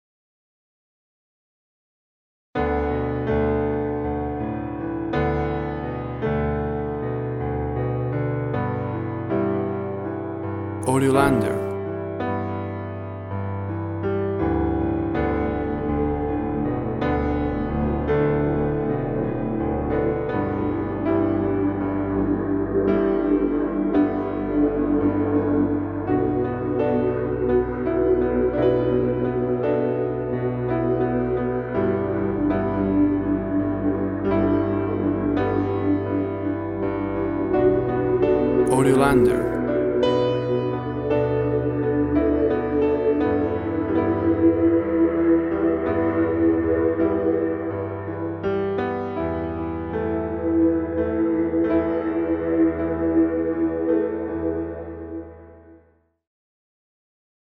very emotional and intense instrumental fragment.
WAV Sample Rate 16-Bit Stereo, 44.1 kHz
Tempo (BPM) 80